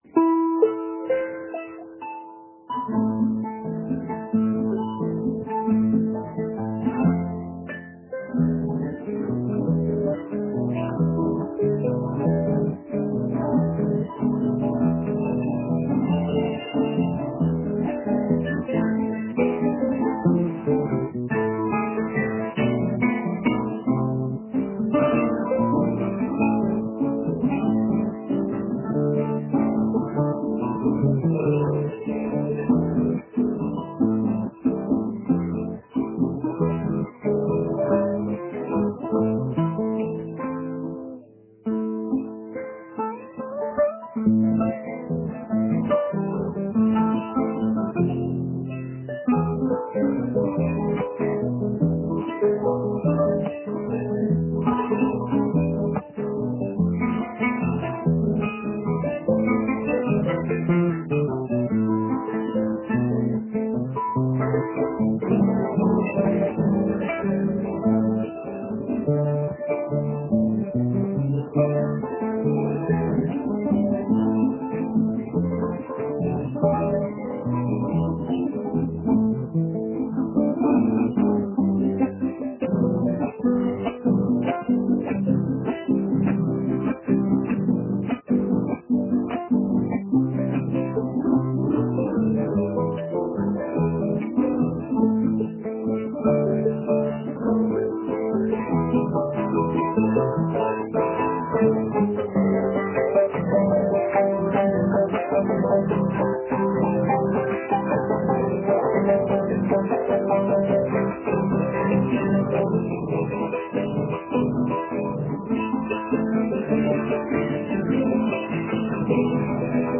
Entre charango, guitarra y cuatro, Jaime Torres, "Lucho" González y Hernán Gamboa se acercaron a Café Torrado para presentar su espectáculo en Montevideo y compartieron con nosotros un café "bien latino".